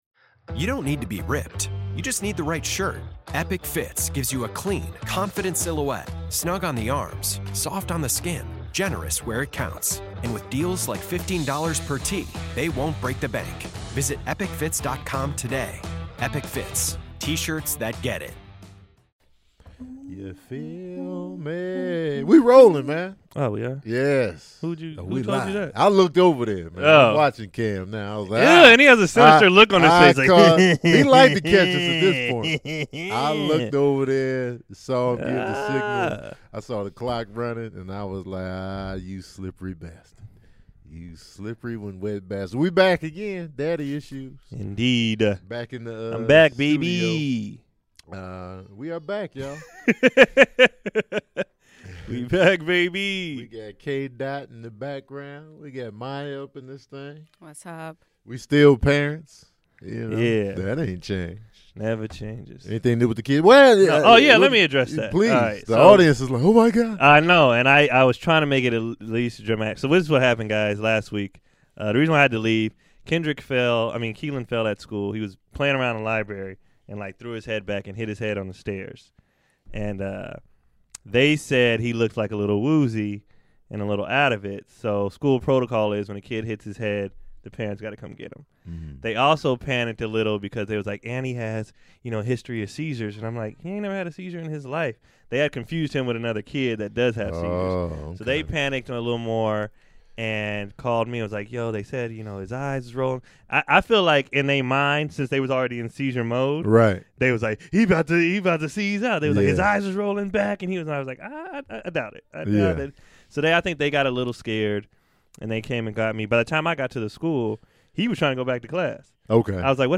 back in the studio. This week we're talking about FAT SHAMING!